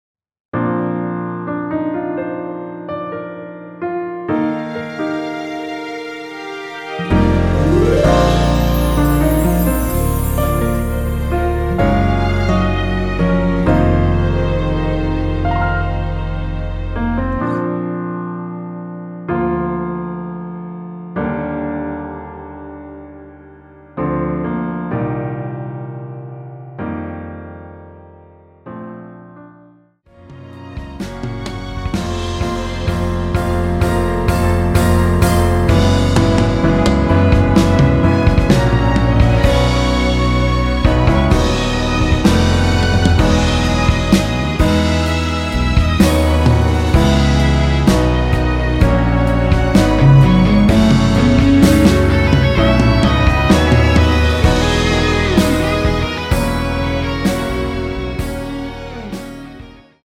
원키에서(-5)내린 MR입니다.
Bb
앞부분30초, 뒷부분30초씩 편집해서 올려 드리고 있습니다.
중간에 음이 끈어지고 다시 나오는 이유는